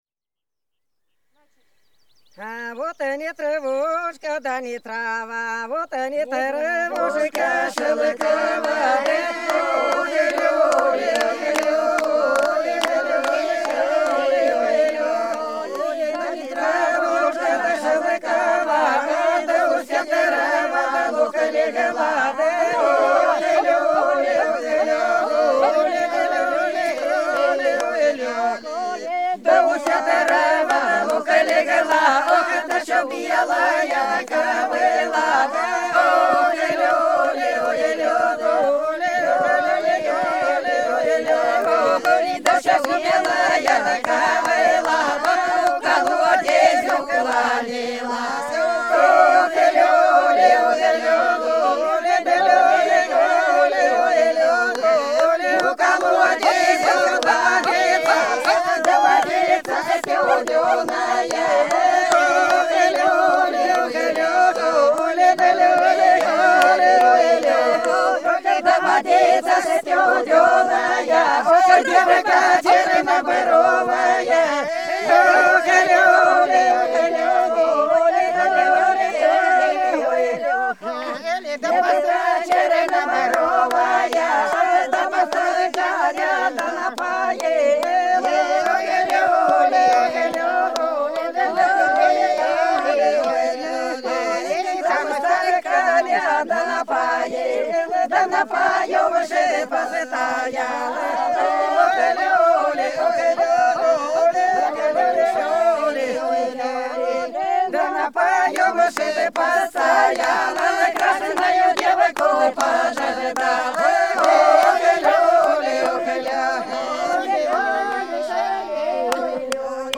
Белгородские поля (Поют народные исполнители села Прудки Красногвардейского района Белгородской области) Ни травушка, ни трава - плясовая, троицкая